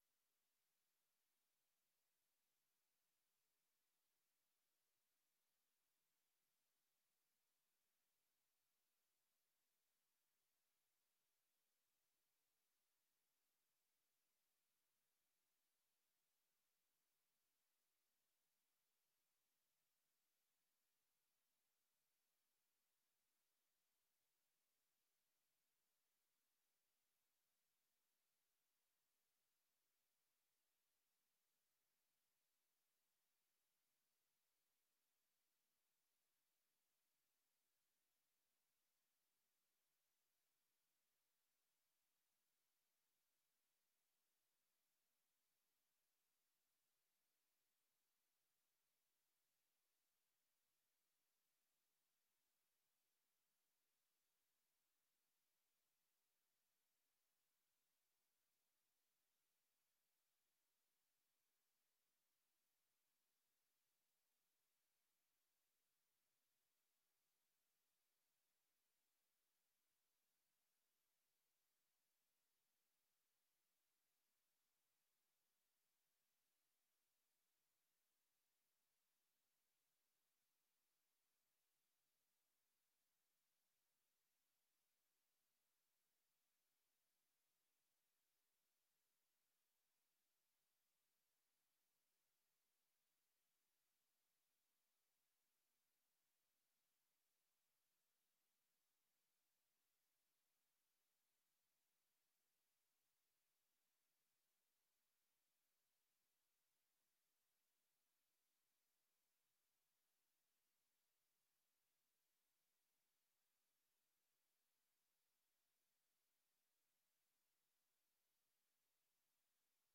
Gemeenteraad 12 mei 2025 20:30:00, Gemeente Roosendaal
Download de volledige audio van deze vergadering
Portefeuillehouder: wethouder De Beer